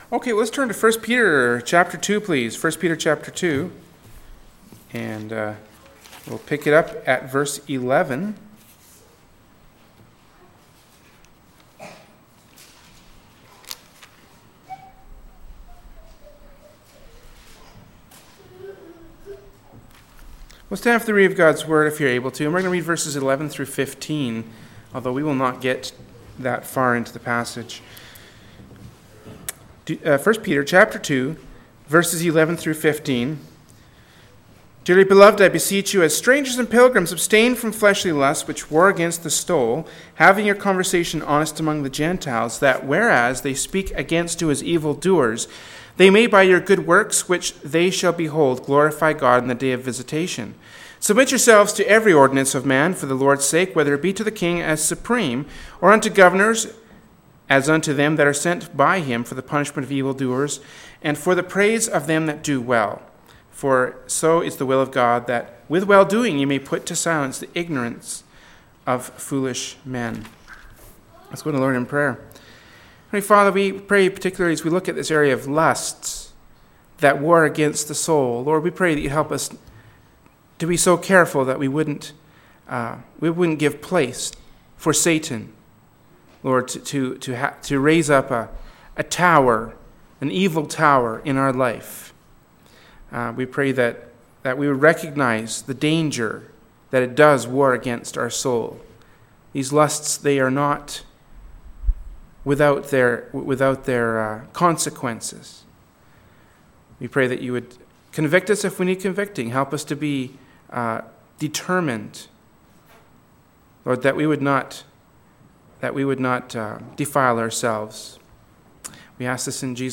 “1st Peter 2:11-15” from Wednesday Evening Service by Berean Baptist Church.